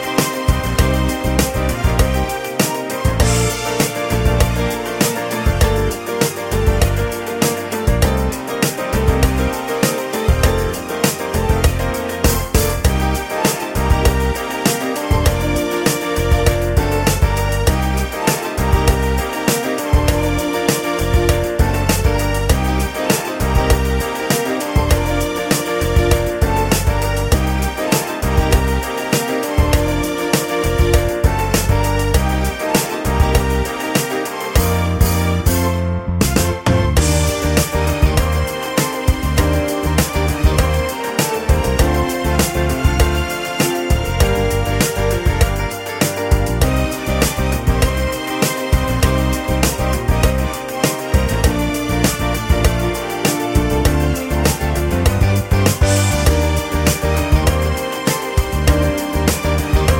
No Backing Vocals Disco 3:25 Buy £1.50